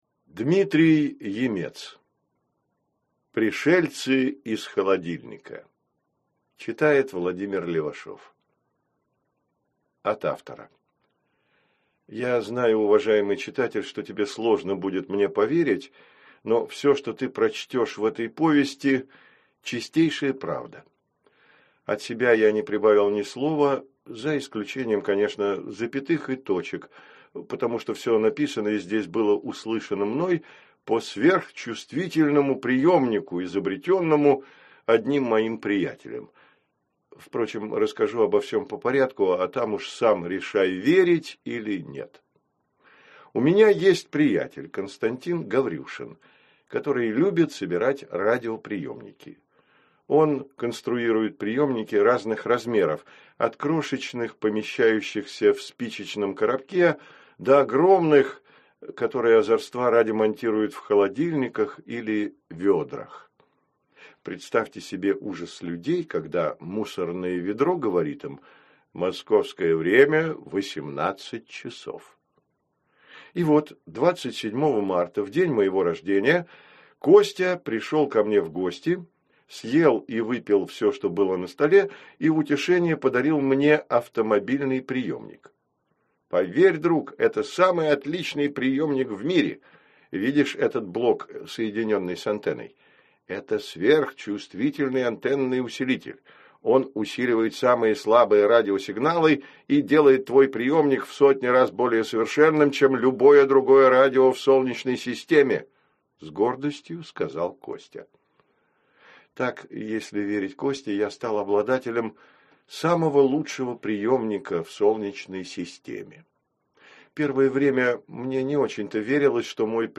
Аудиокнига Пришельцы из холодильника | Библиотека аудиокниг
Прослушать и бесплатно скачать фрагмент аудиокниги